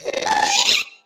sounds / mob / ghast / charge.ogg
charge.ogg